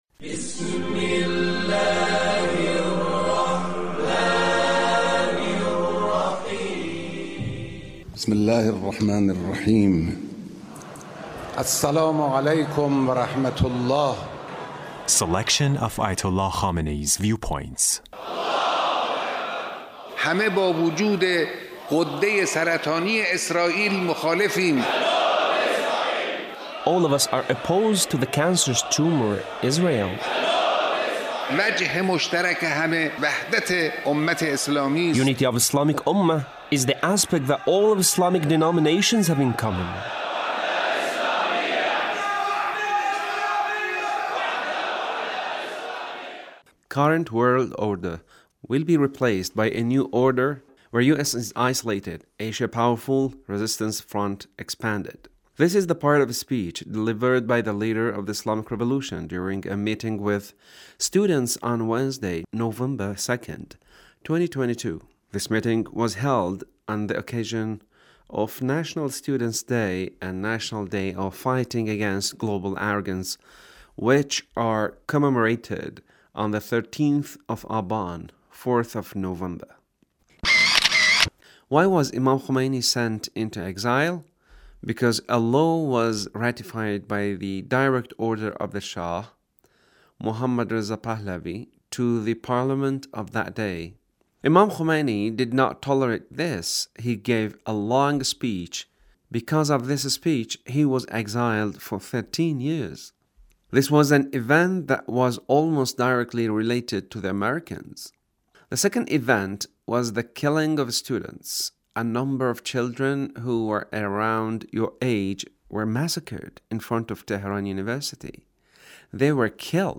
Leader's Speech on 13th of Aban